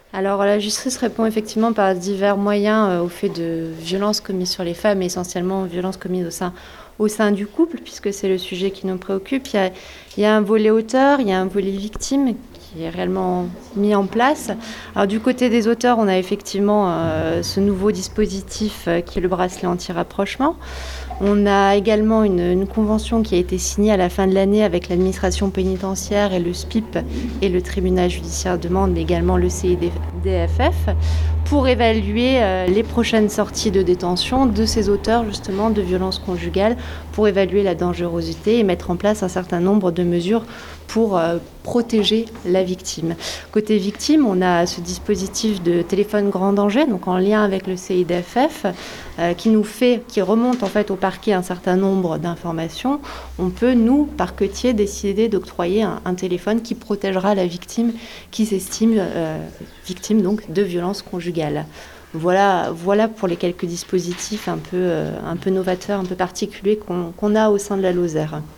Et pour faire face à cette recrudescence des chiffres, l’arsenal juridique doit être à la hauteur. Margot Gneiting substitut du Procureur de la République.